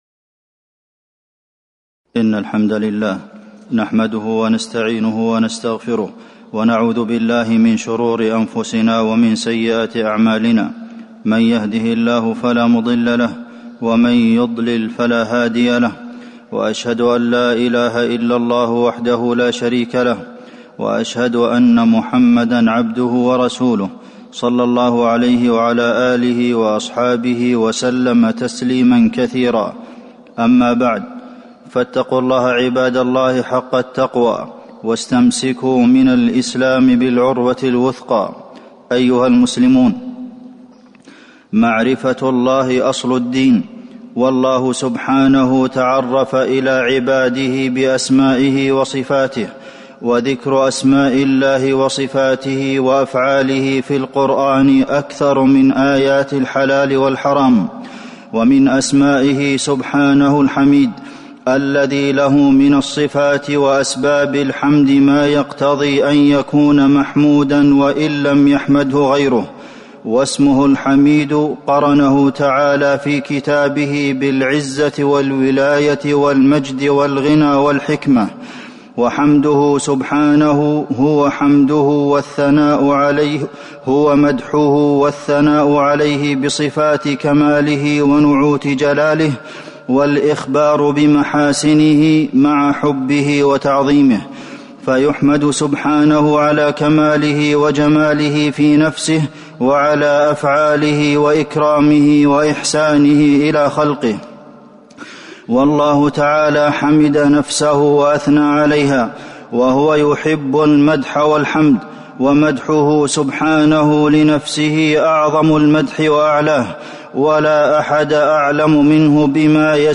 تاريخ النشر ٢ ربيع الأول ١٤٤٣ هـ المكان: المسجد النبوي الشيخ: فضيلة الشيخ د. عبدالمحسن بن محمد القاسم فضيلة الشيخ د. عبدالمحسن بن محمد القاسم الحمد